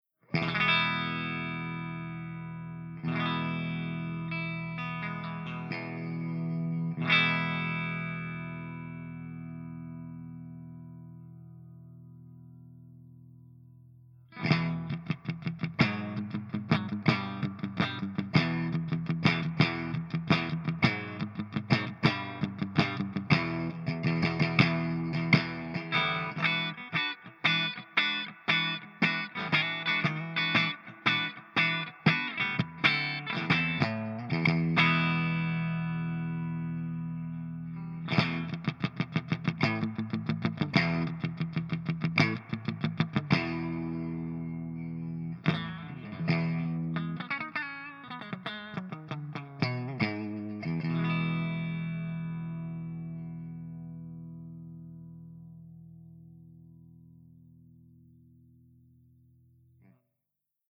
094_HIWATT_STANDARDCLEAN_GB_HB.mp3